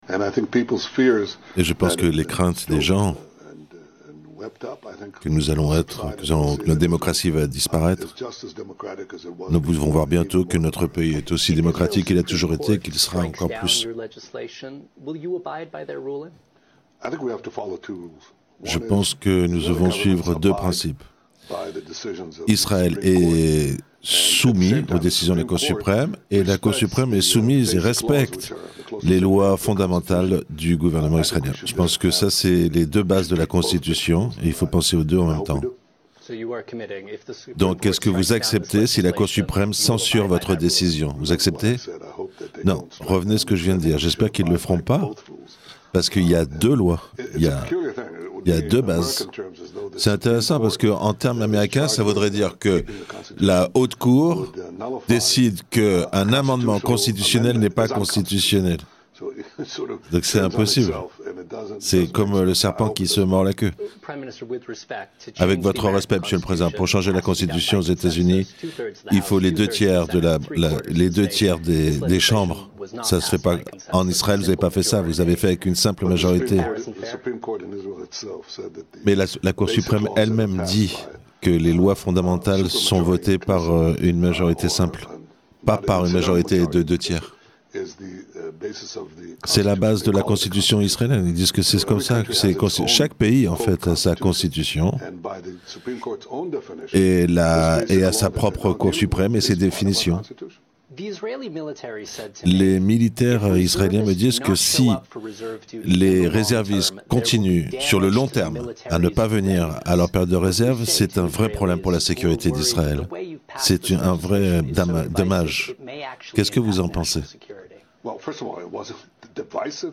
Interview traduite en français de l'interview accordée par Benjamin Netanyahou à la chaine NBC